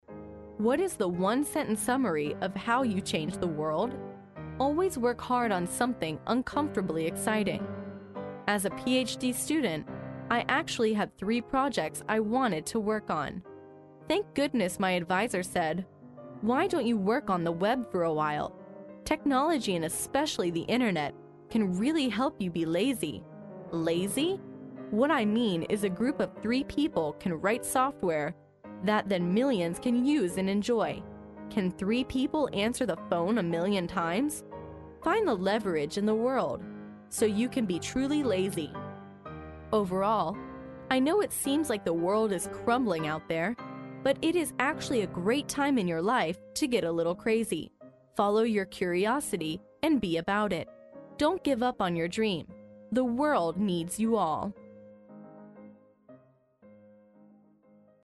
在线英语听力室历史英雄名人演讲 第35期:追逐远大的梦想(2)的听力文件下载, 《历史英雄名人演讲》栏目收录了国家领袖、政治人物、商界精英和作家记者艺人在重大场合的演讲，展现了伟人、精英的睿智。